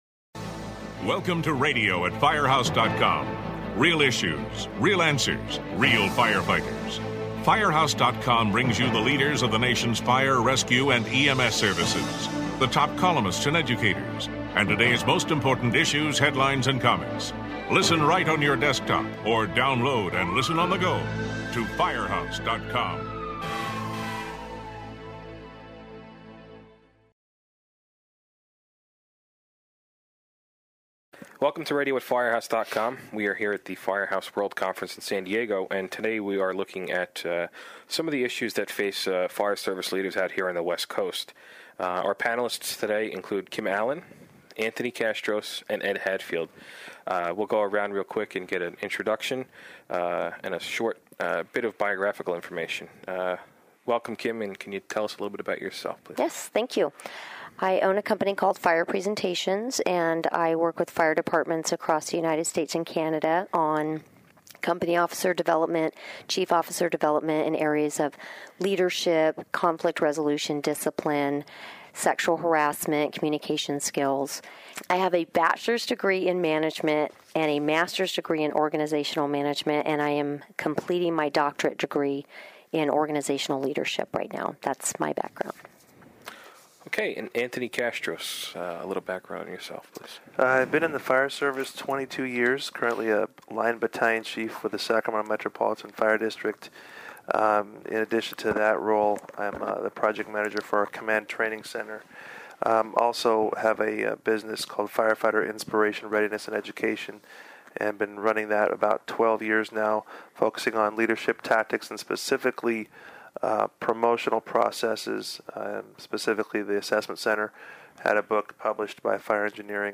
This round table podcast explores several issues that face fire officers, especially on the West Coast.